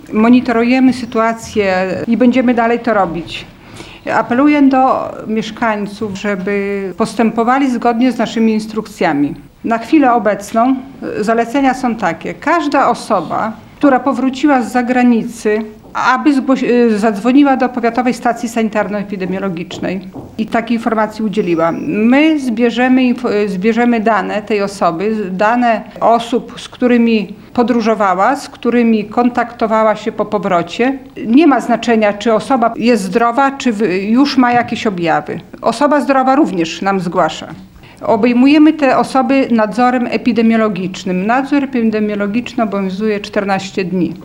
– Każdy, kto powrócił z zagranicy, powinien poinformować o tym Sanepid – powiedziała Elżbieta Bednarko, Powiatowy Inspektor Sanitarny w Suwałkach.